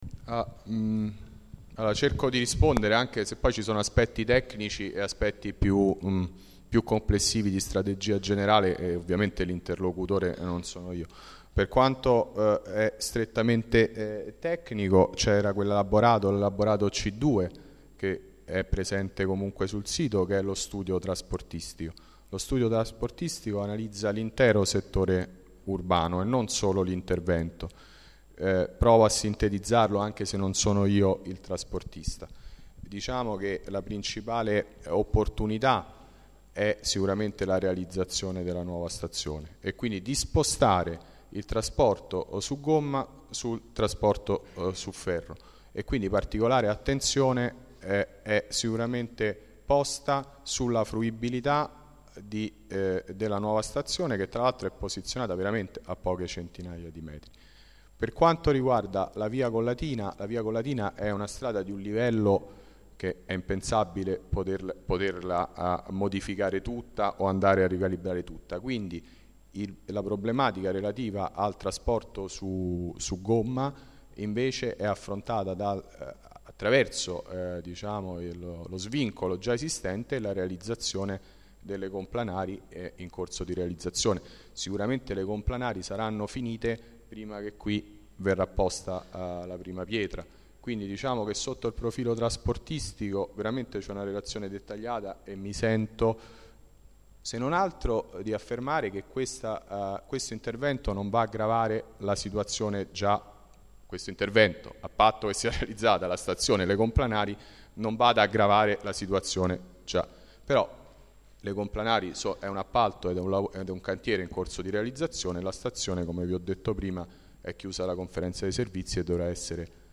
Assemblea
Registrazione integrale dell'incontro svoltosi il 4 aprile 2013 presso la sala consiliare del Municipio Roma VIII